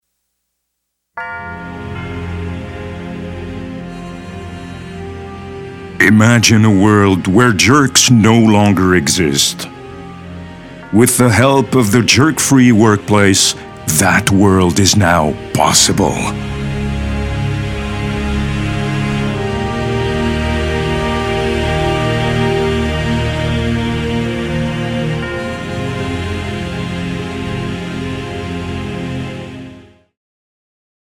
Jerk-Free-Workplace_Narration.mp3